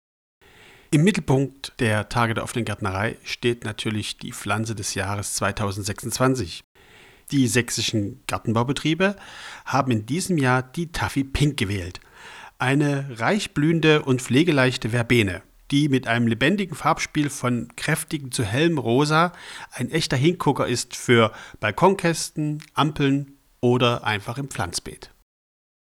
O-Ton-Download: